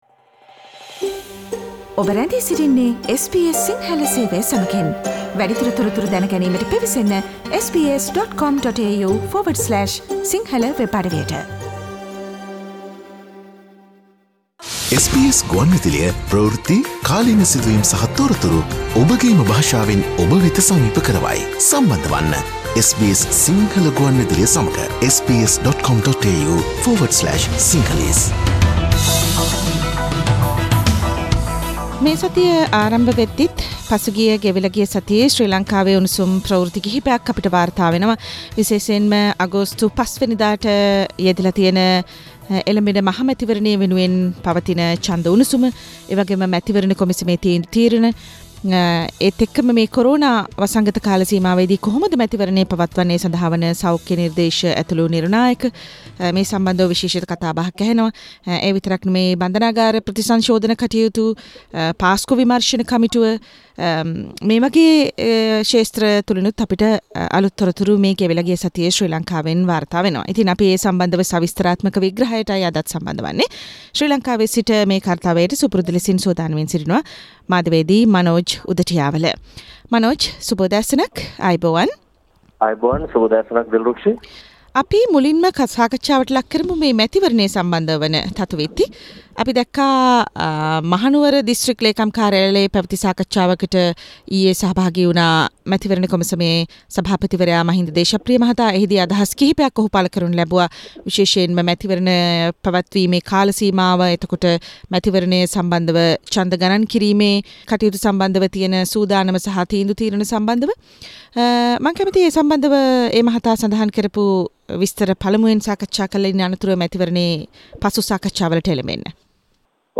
SBS Sinhala radio brings you a comprehensive wrap up of the highlighted news from Sri Lanka with Journalist – News and current affair